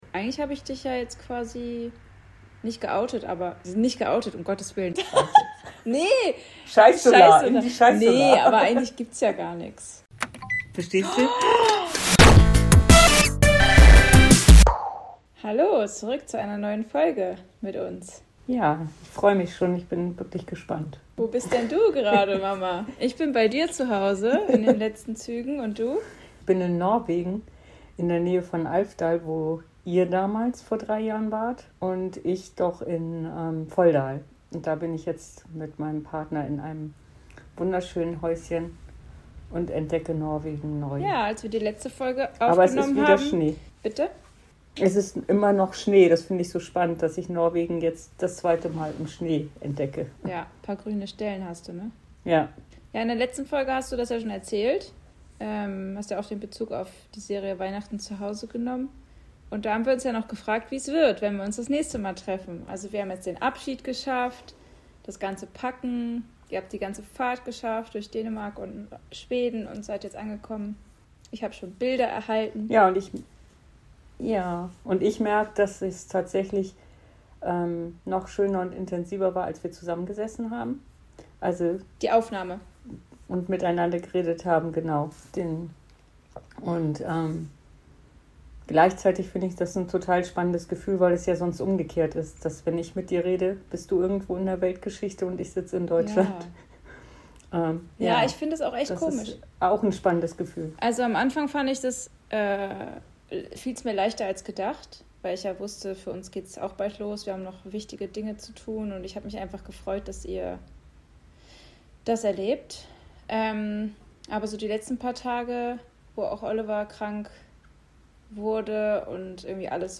Ehrliches Mutter-Tochter-Gespräch über Beziehung, Konflikte, Heilung und ein tiefes Thema, über das viele schweigen